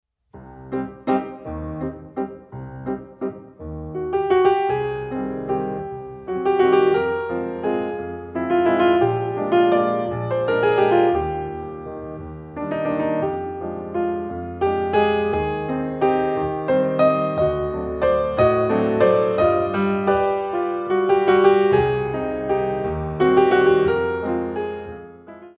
Grand Allegro 1